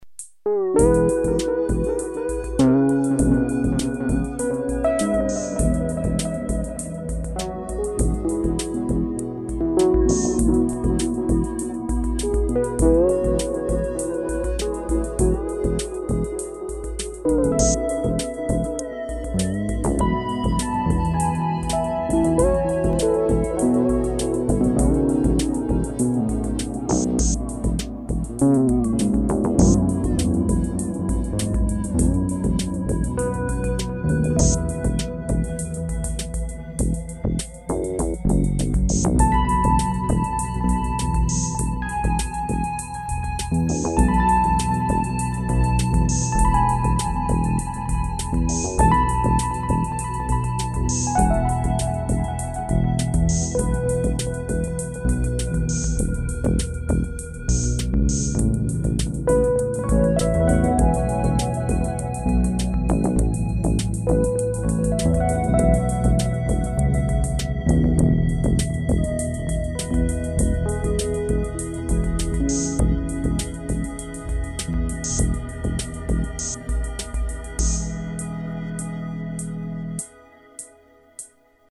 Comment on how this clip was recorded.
At this point I'd moved past the 4-track recorder and was recording everything digitally onto the PC.